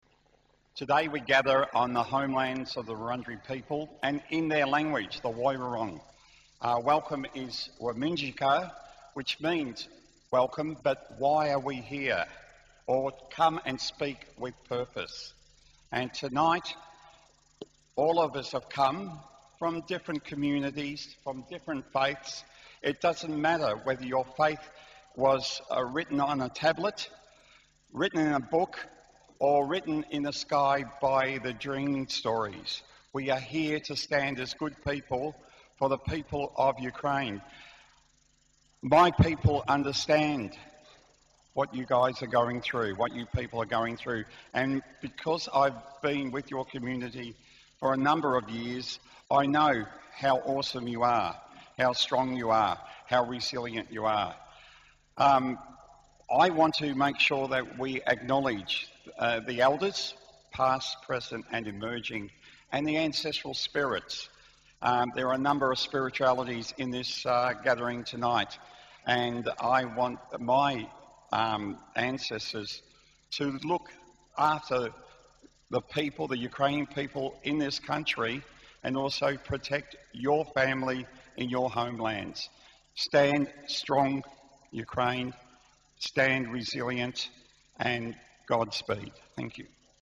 Prayer for peace in Ukraine. Ukrainian Catholic Cathedral of the Holy Apostles Peter and Paul.